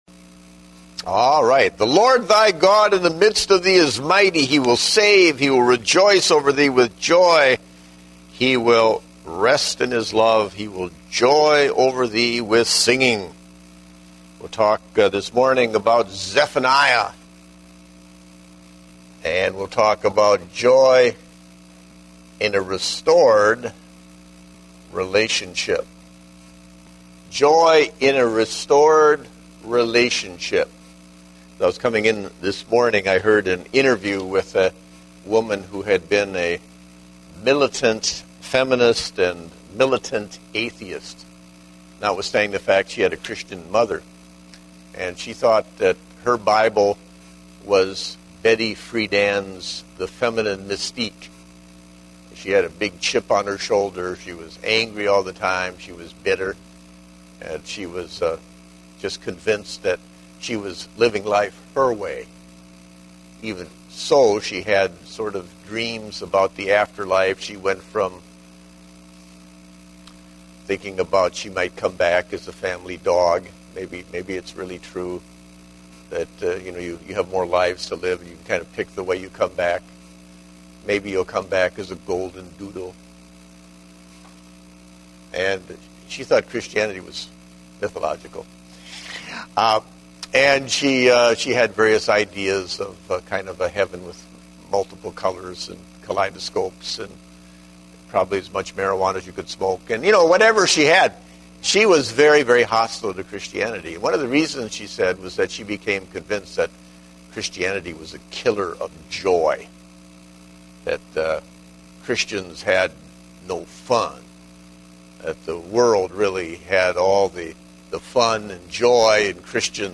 Date: December 26, 2010 (Adult Sunday School)